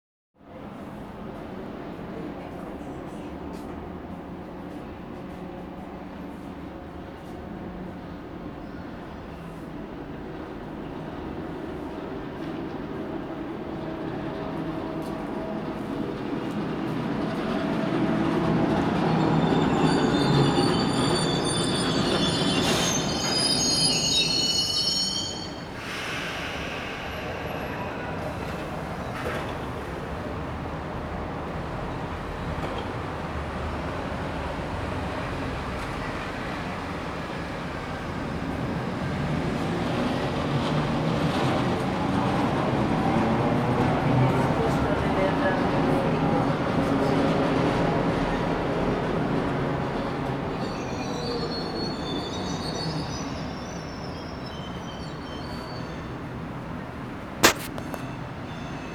I rumori di ferraglia che vengono dal passato
Queste sono delle registrazione effettuate da un appartamento al sesto piano di un palazzo che si affaccia sulla ferrovia alle 22,50 e alle 23 del 24/06/2015 : immaginate cosa si possa sentire nei piani inferiori !